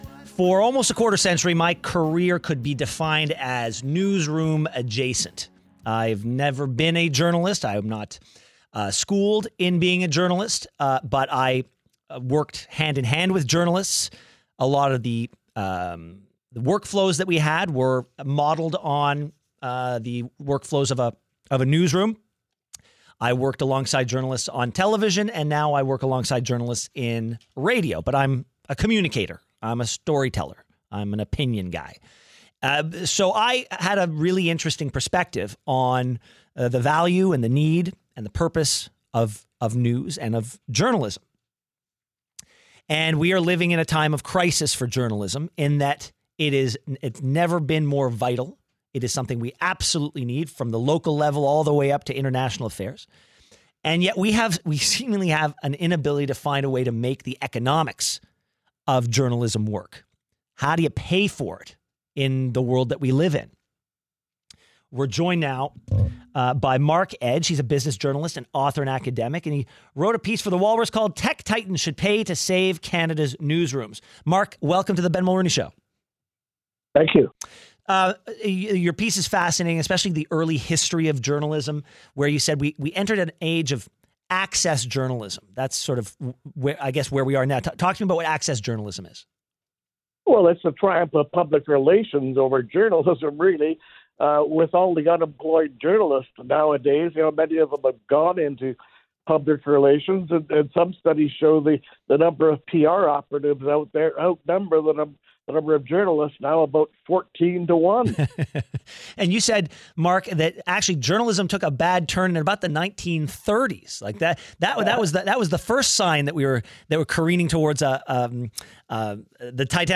02/12/24 Interview with Ben Mulroney